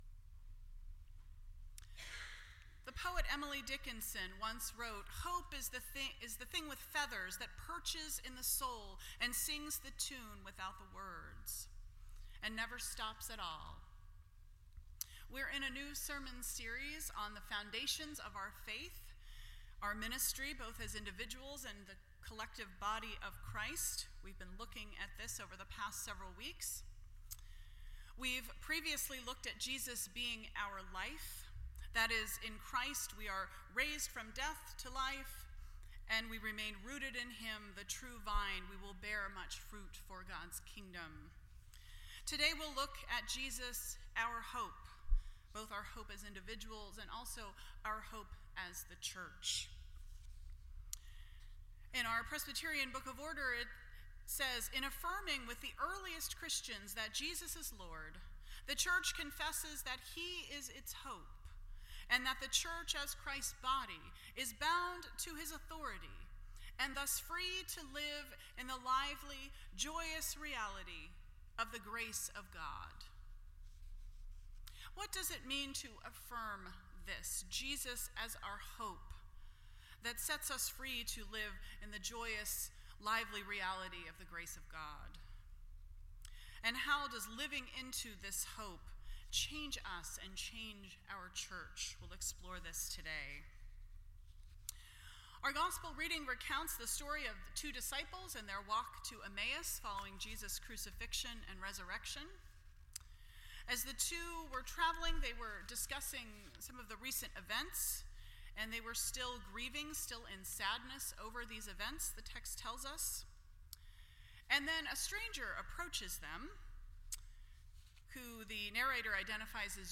Foundations Service Type: Sunday Morning %todo_render% Share This Story